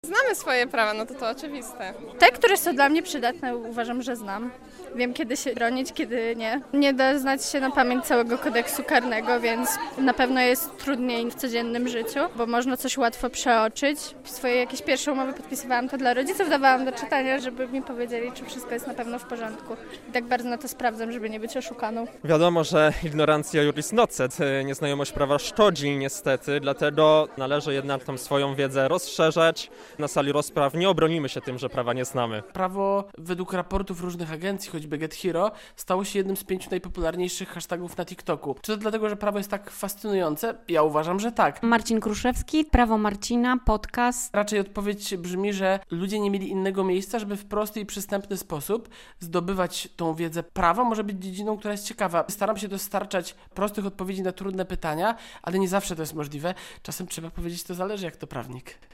Wydziałową aulę wypełnili studenci i uczniowie z podlaskich szkół ponadpodstawowych, którzy rozważają możliwość studiowania prawa.